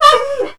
I wanted Volkov & Chitzkoi voices, but wasn't sure what to use, so i decided to take the C&C Remastered RA1 voice lines and triplex them, with some adjustments, to make them cybornetic sounding.